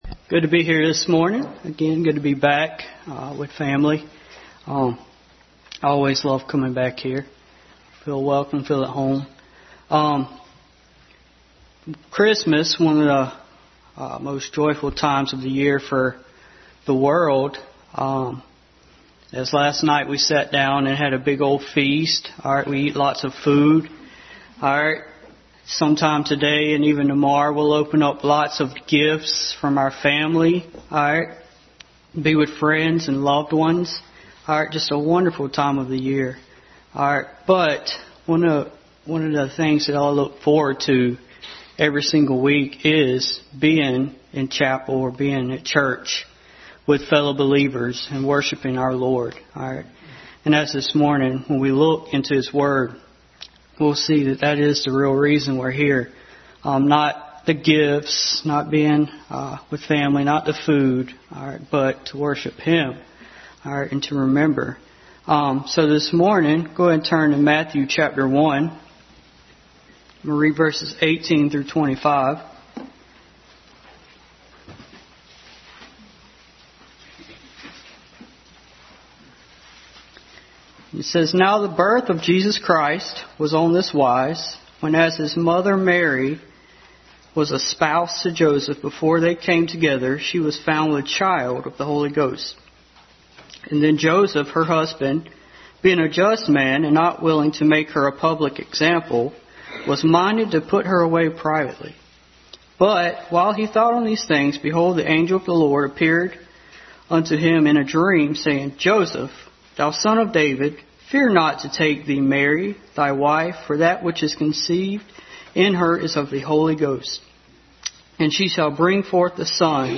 Matthew 1 Service Type: Family Bible Hour Bible Text